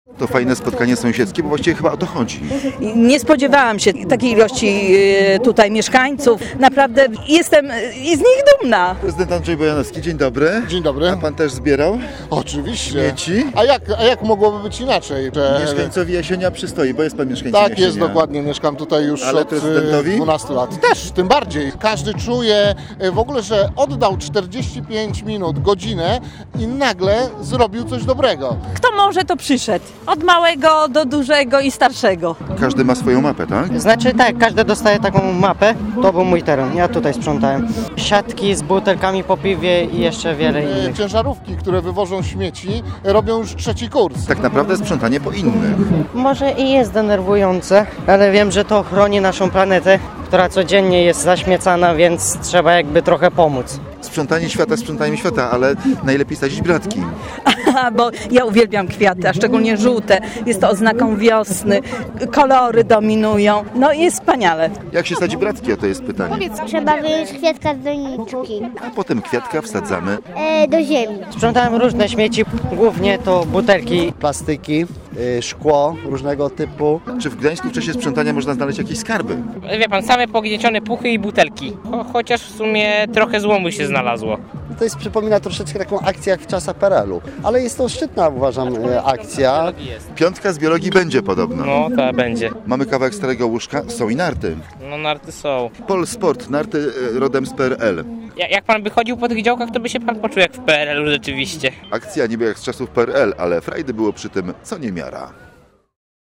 – Nic z tych rzeczy mówili mieszkańcy.